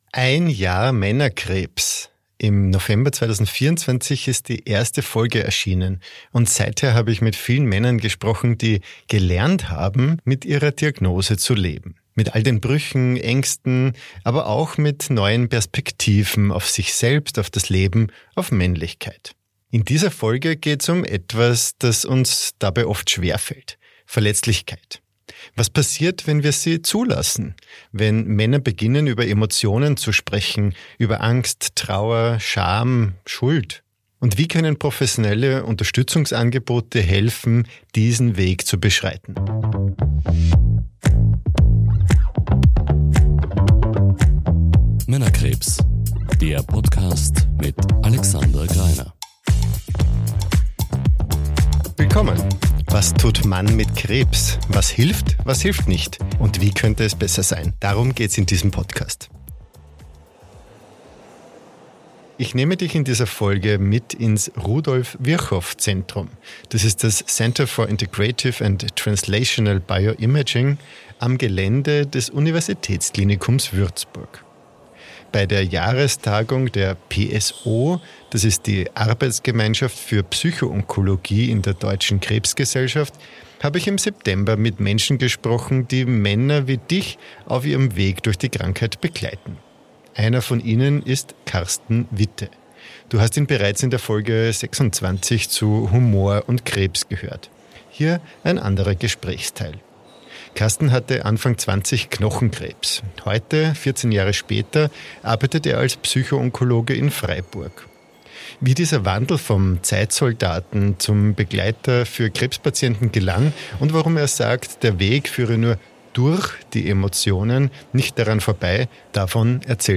Was passiert, wenn wir Männer anfangen würden, über Angst, Trauer oder Scham zu sprechen und die eigene Verletzlichkeit plötzlich auch für uns selbst greifbar wird? Zum 1. Geburtstag von Männerkrebs eine Reportage von der PSO-Jahrestagung in Würzburg.